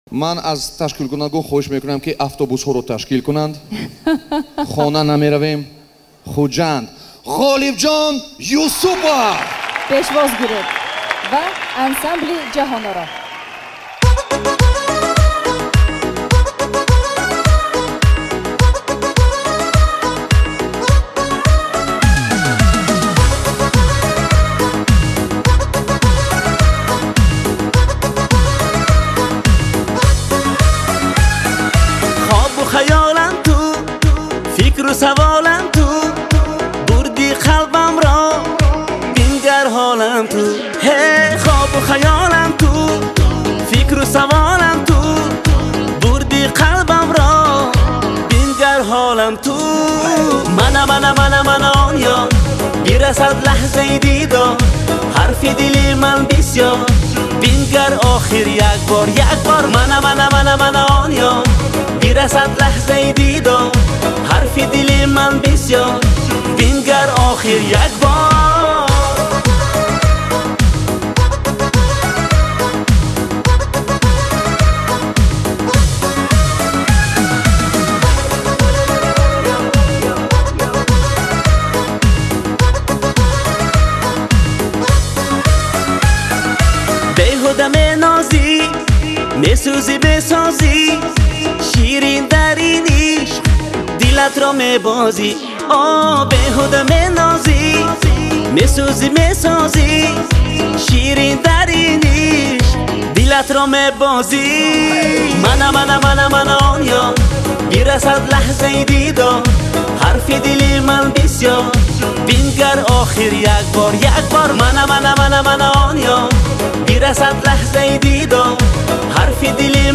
Категория: Таджикские